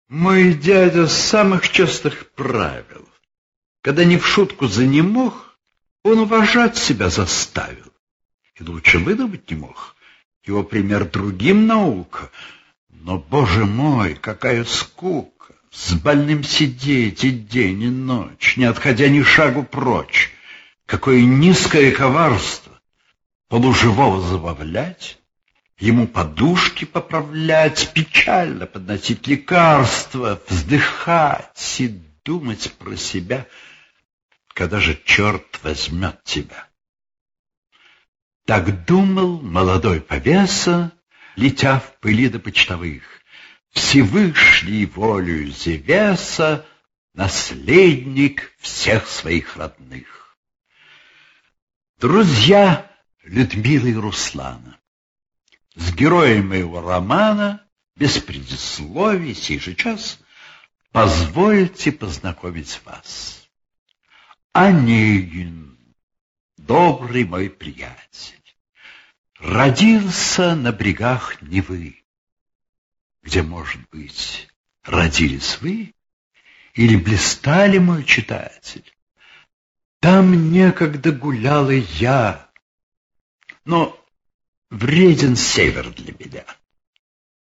файл) 1,17 Мб Пушкин А.С. "Евгений Онегин". 1823-1830. Художественное чтение в исполнении Юрия Завадского.